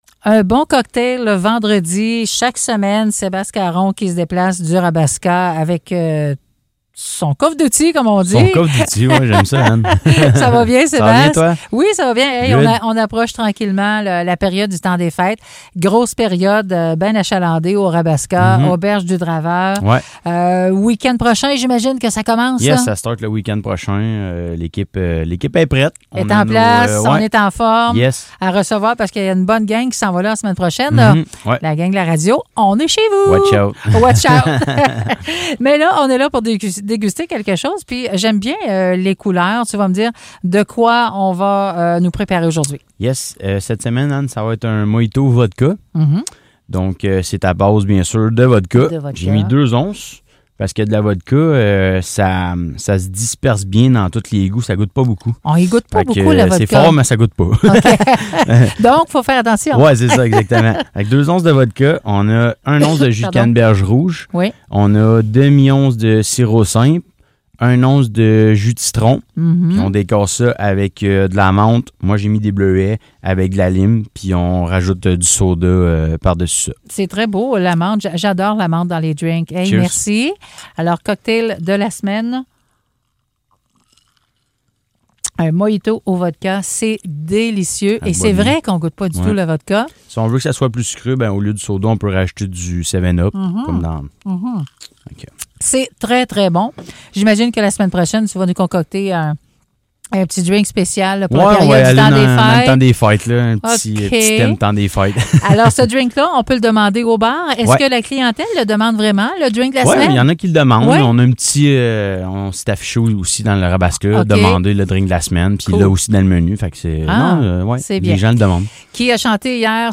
en studio pour nous présenter le cocktail du vendredi, les musiciens de la fin de semaine et nous rappel la présence de Matt Lang à l’Auberge du Draveur en décembre pour son spectacle All Night Longer.